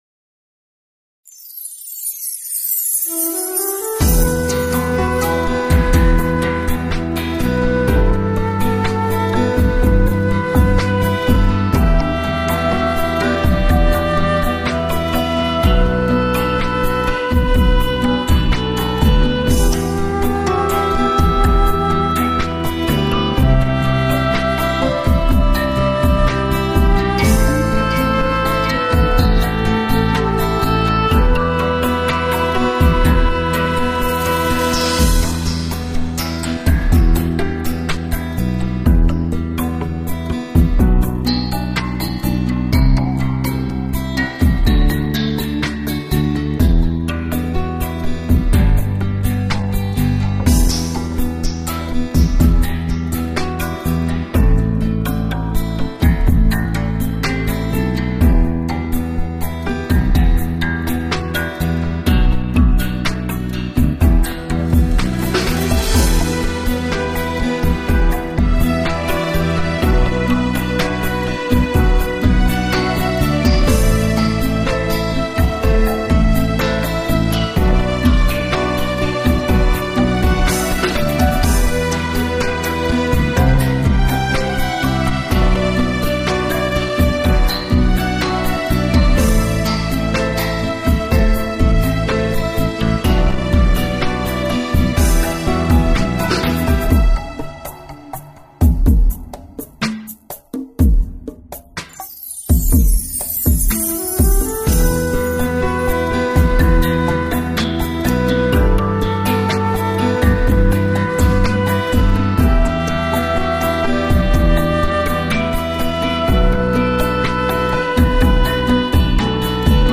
无 调式 : G 曲类